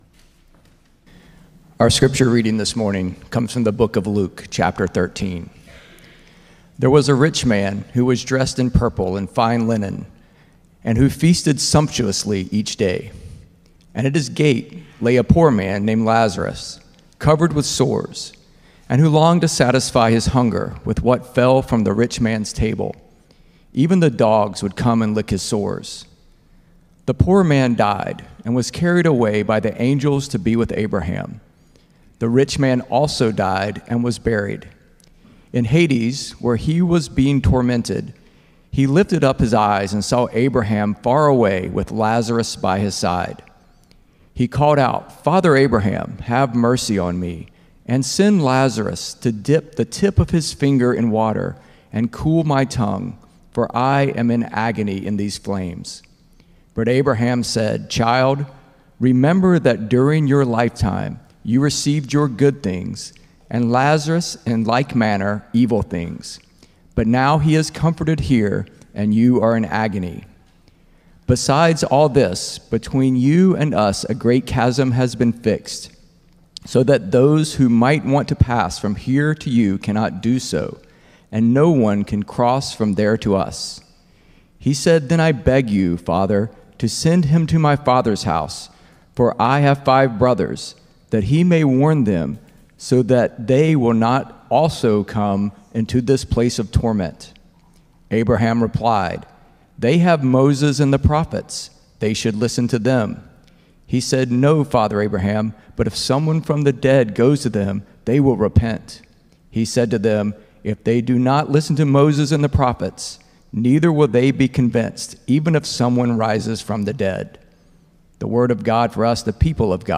“Red Letter Life” Sermon Series, Week 4